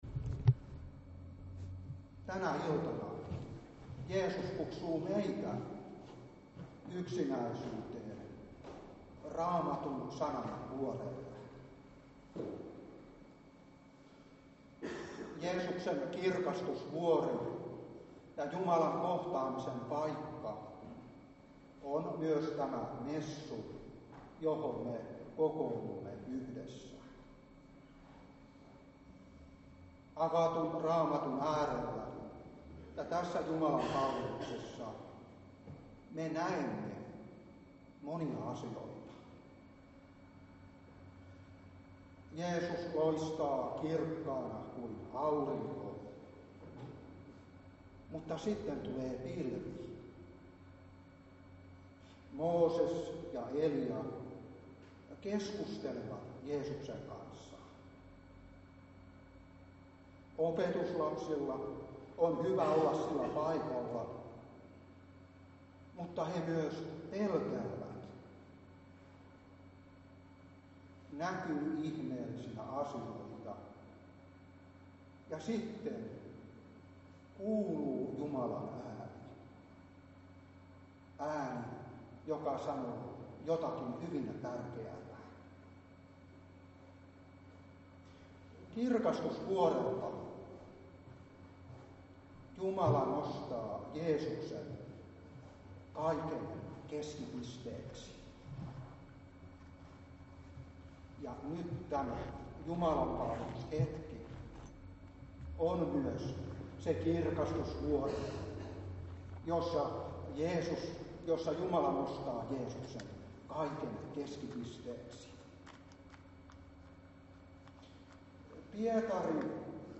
Saarna 2016-7.